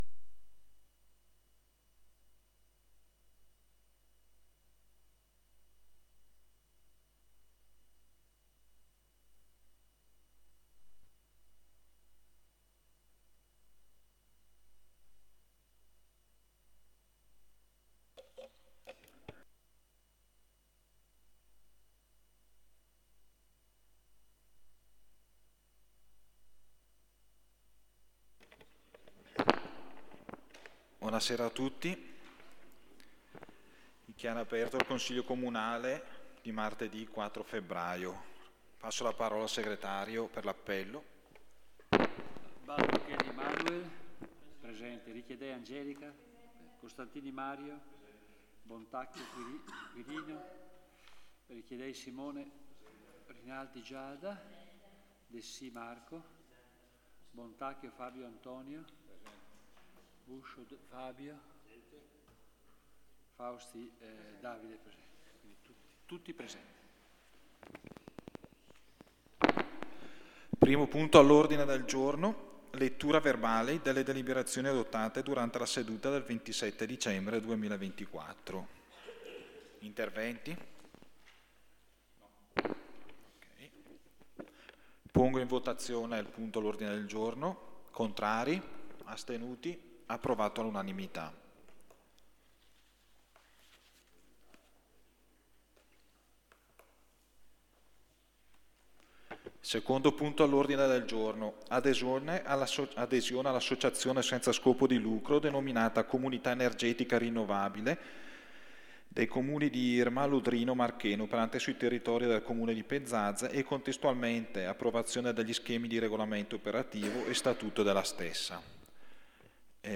Registrazioni Sedute Consiglio Comunale
La sezione contiene le registrazioni delle sedute del Consiglio Comunale